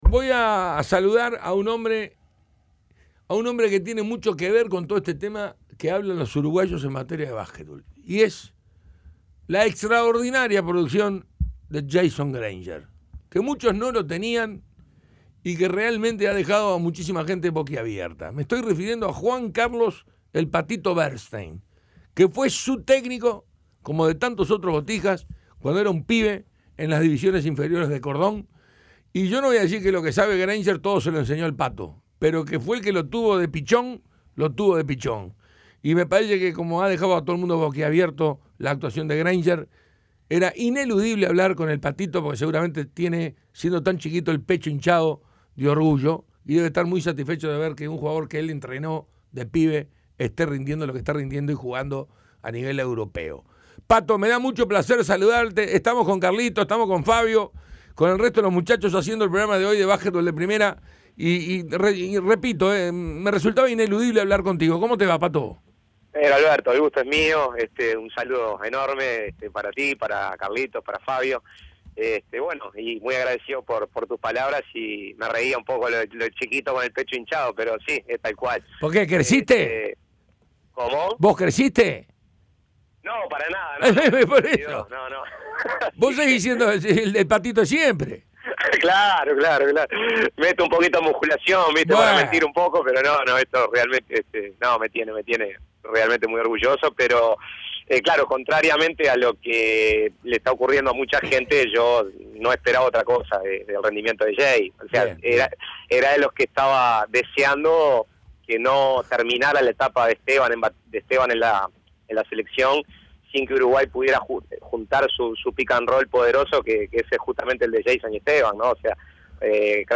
Entrevista completa.